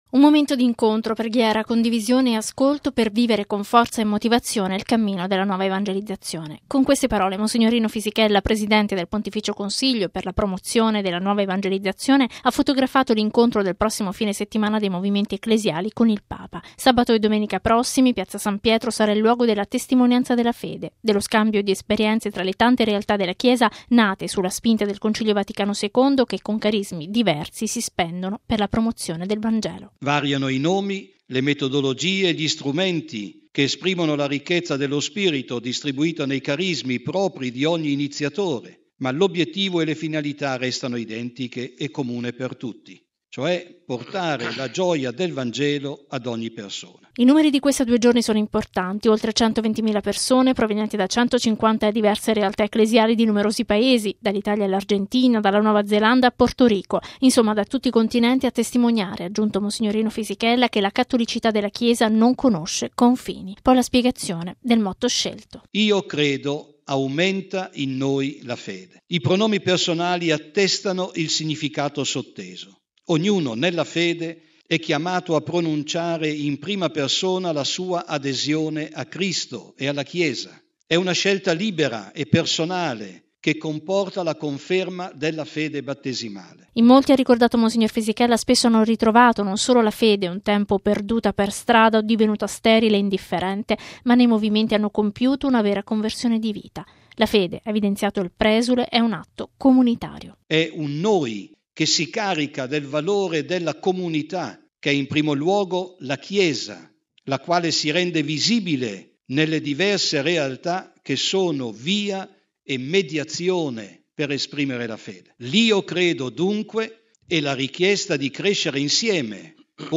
A renderlo noto è stato mons. Rino Fisichella, presidente del Pontificio Consiglio per la Promozione della Nuova Evangelizzazione, che oggi in Sala Stampa vaticana ha presentato questa importante iniziativa per l’Anno della Fede.